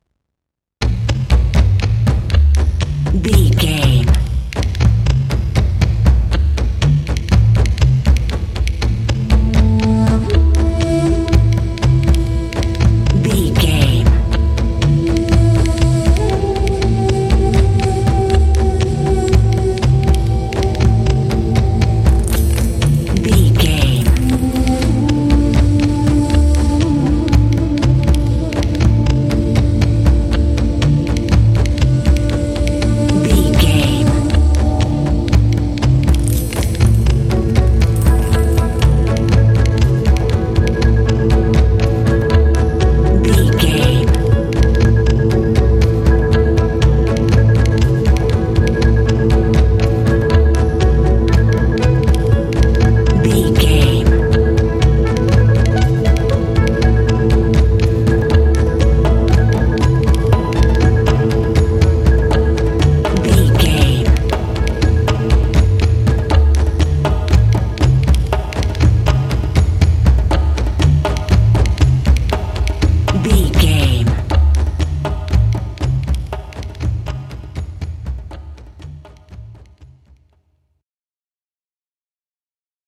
Ionian/Major
sentimental
dreamy
piano
strings
cinematic
film score